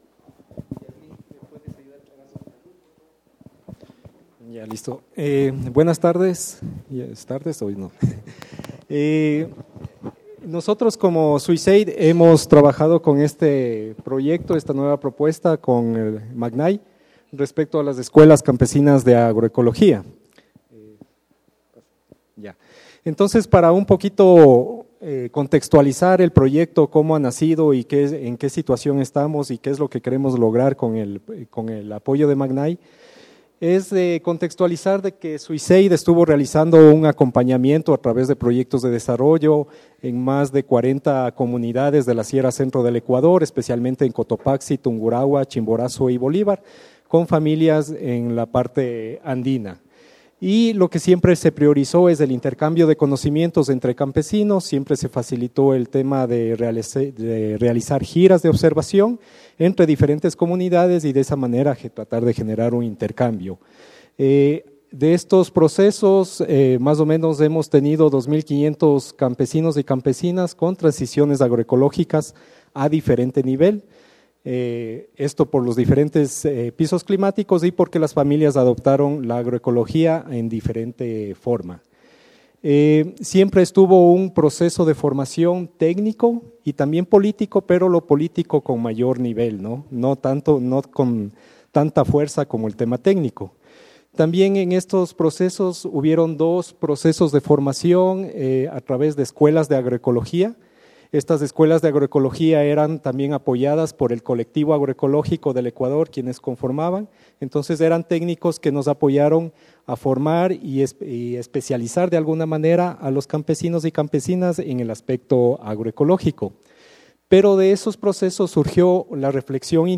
Presentacion-Proyecto-Swissaid-Primera-Jornada.mp3